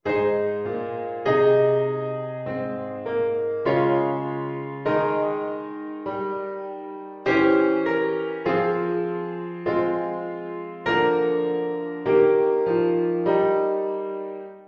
goede verdeling van de stemmen